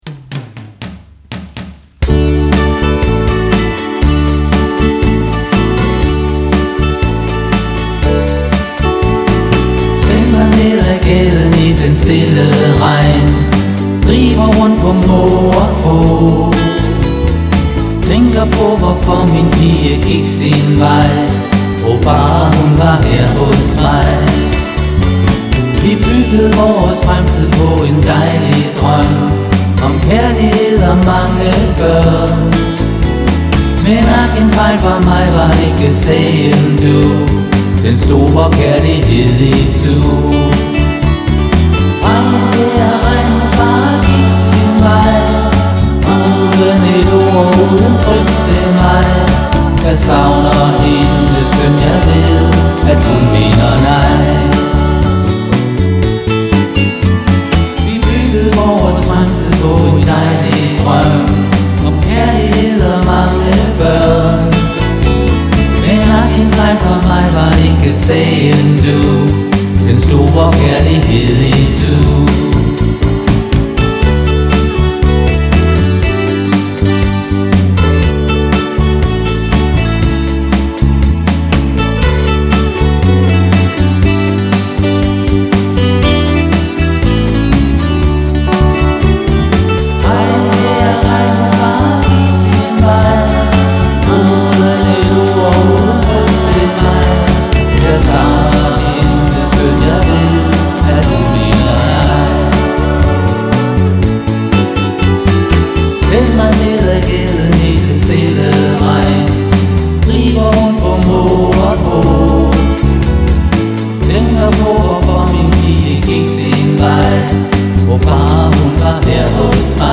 Rock'n'roll fra gamle dage til seniorer.
• Coverband